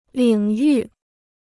领域 (lǐng yù) Free Chinese Dictionary